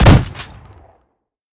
pump-shot-4.ogg